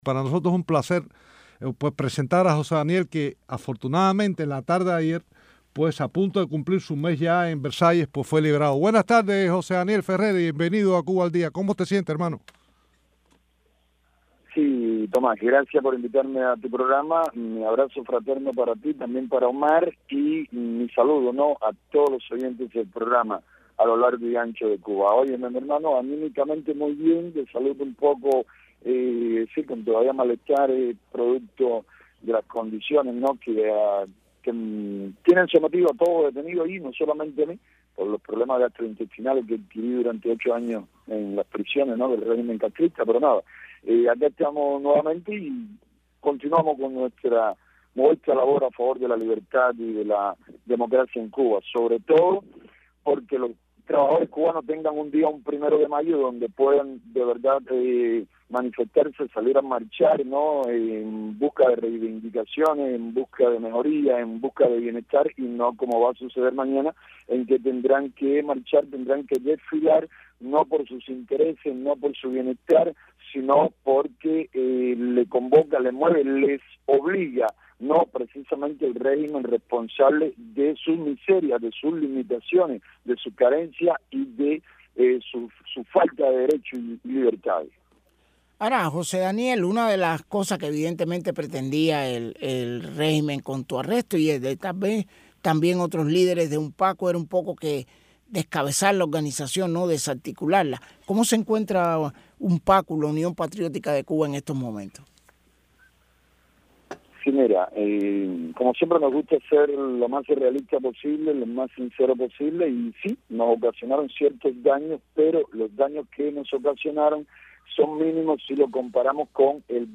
entrevistan a José Daniel Ferrer de la Unión Patriótica de Cuba que fue puesto en libertad el domingo.